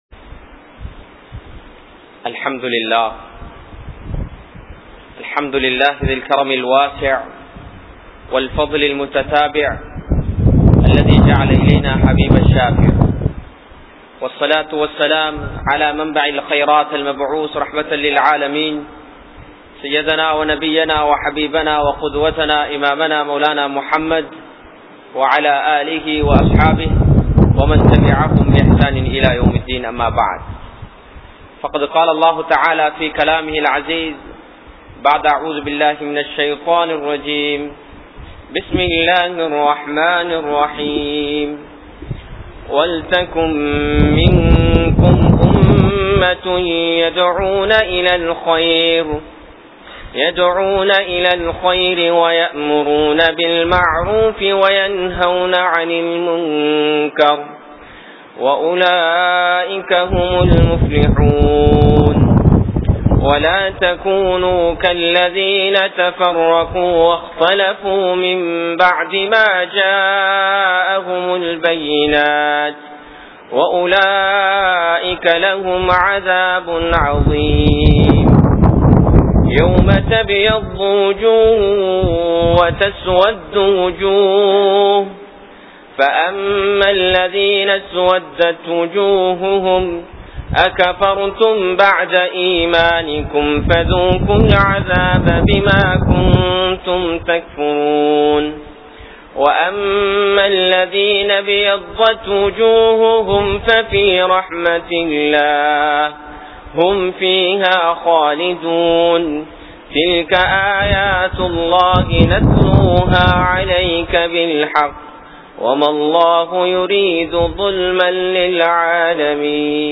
Mattravarhalai Mathiungal (மற்றவர்களை மதியுங்கள்) | Audio Bayans | All Ceylon Muslim Youth Community | Addalaichenai